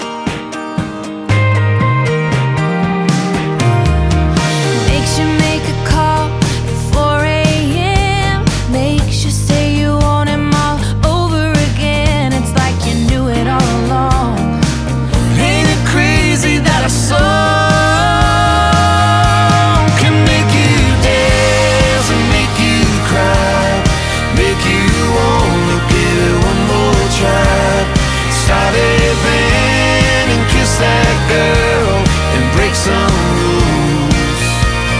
Country
2024-06-24 Жанр: Кантри Длительность